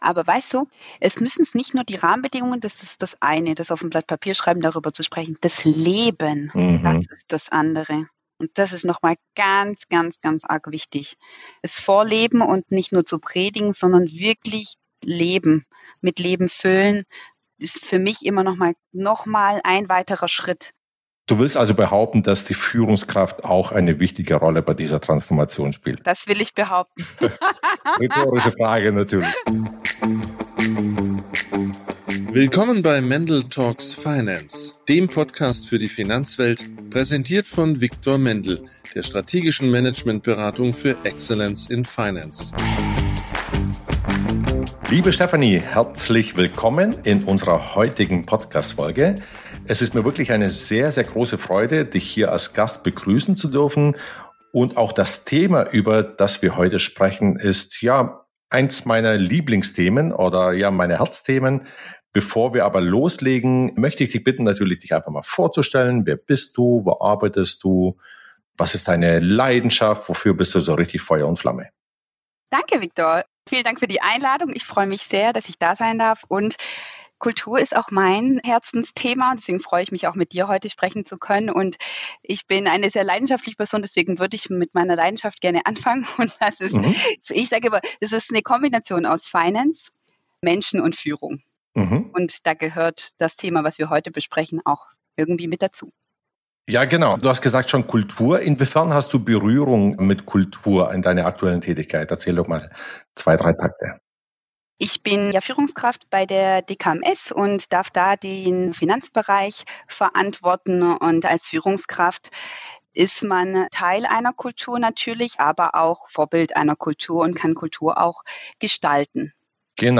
Ein paar Highlights aus unserem Gespräch: Kultur ist messbar: in Fluktuationsquoten, Krankheitsraten, Prozessgeschwindigkeit und Qualität der Arbeitsergebnisse.